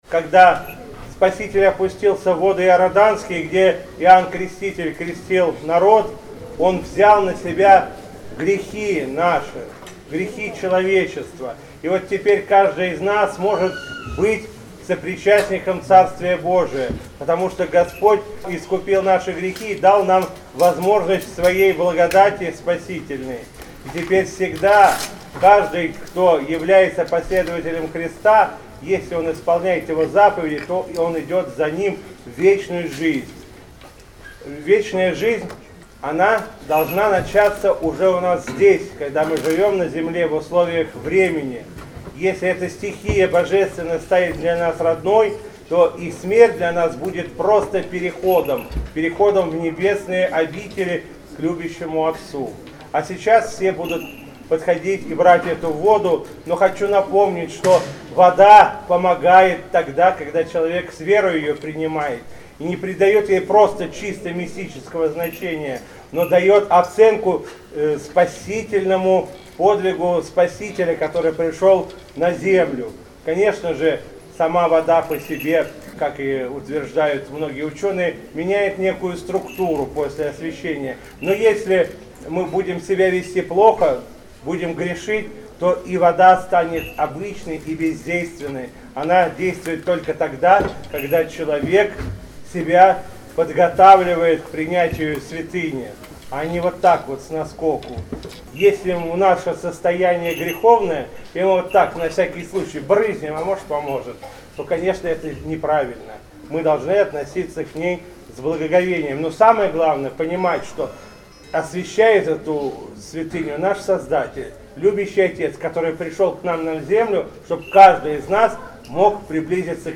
Затем владыка Игнатий обратился к верующим с архипастырским словом о празднике Крещения и о крещенской воде.